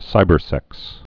(sībər-sĕks)